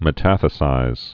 (mĭ-tăthĭ-sīz)